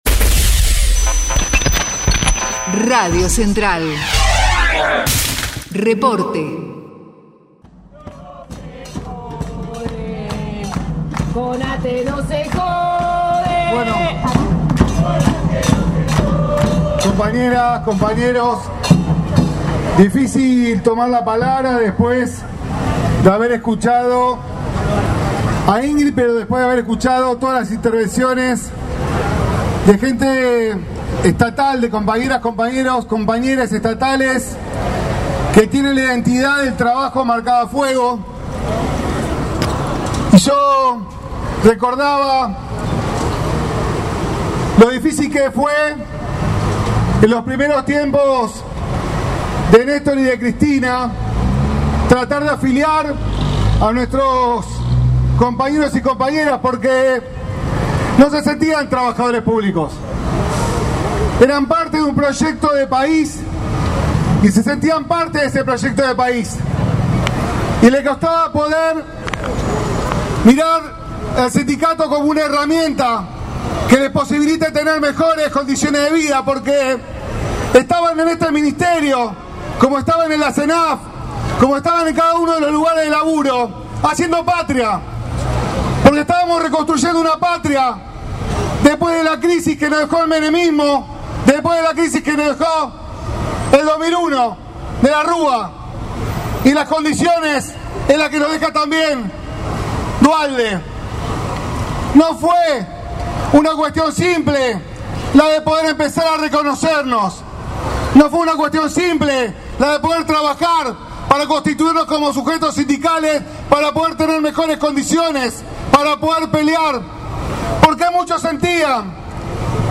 Con una radio abierta frente al edificio que tiene las imágenes de Evita, en Belgrano y 9 de Julio, lxs compañerxs estatales reclamaron también la reubicación de los y las compañeras en situación de disponibilidad y rechazaron las transferencias compulsivas.
ATE EN LUCHA: TESTIMONIO